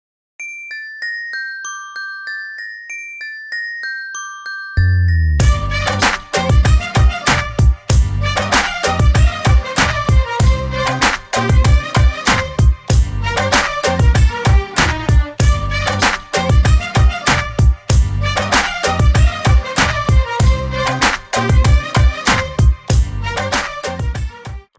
• Качество: 320, Stereo
рэп